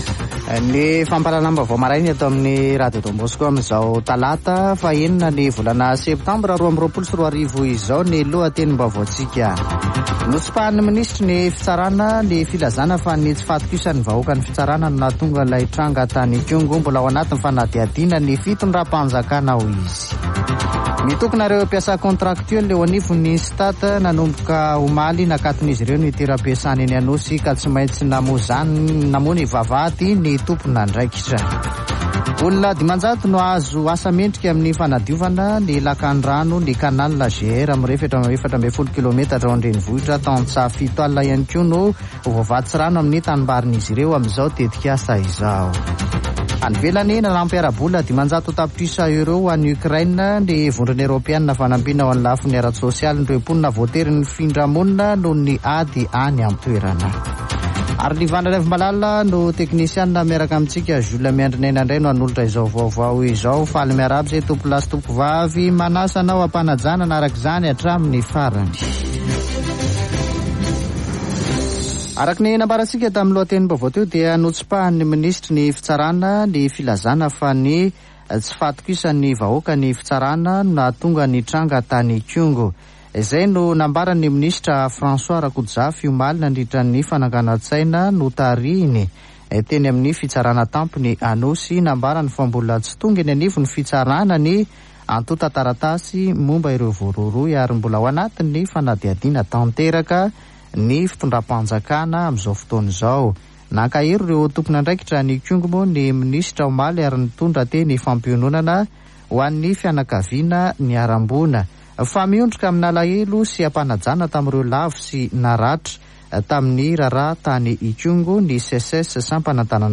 [Vaovao maraina] Talata 06 septambra 2022